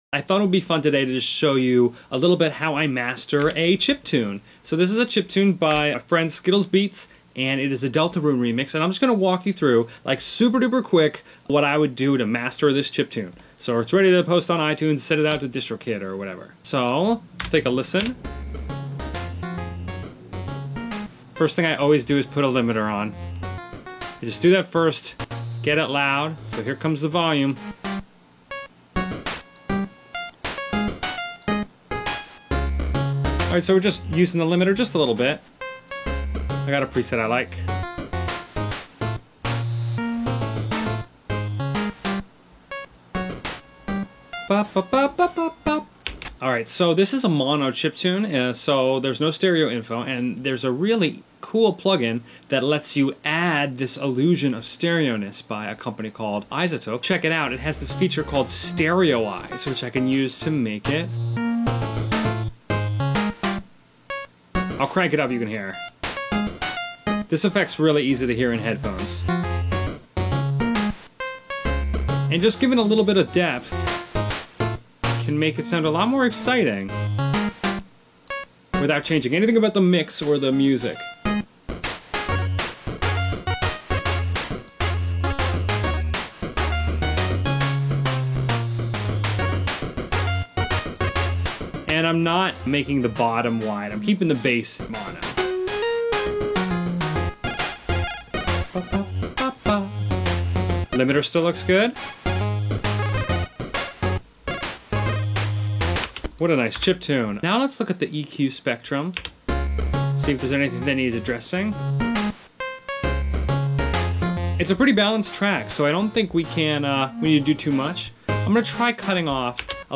chiptune
How_to_MASTER_CHIPTUNE_in_10_MINUTES.mp3